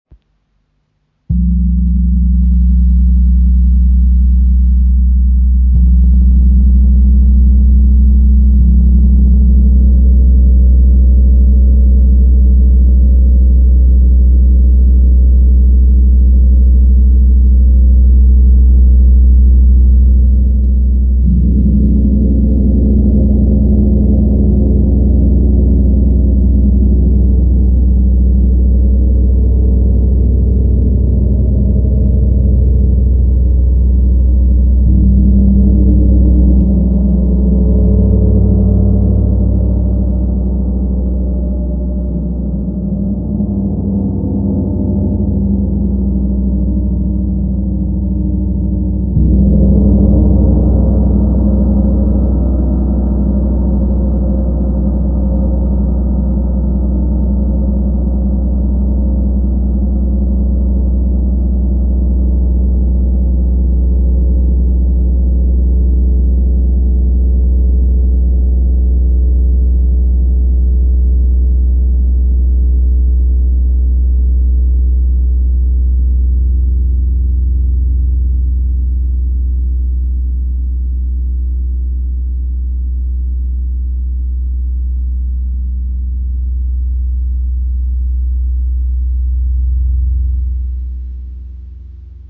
Chinesischer Wind Gong – Einzigartiger Klang für Meditation, Heilung und Konzerte • Raven Spirit
Klangbeispiel
Ein sanfter Schlag – und der Gong erwacht. Sein Klang breitet sich aus, schwebend wie der Wind, tief, klar und durchdringend. Der Wind Gong aus hauchdünner Bronze hat eine besondere Resonanz, die sanfte, fast mystische Töne erzeugt, aber auch kraftvolle, tiefgehende Vibrationen.
Weiterlesen Klangbeispiel Feng Gong 100cm